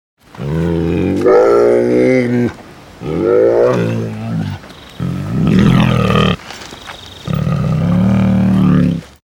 Приклад звуку "Бурий ведмідь"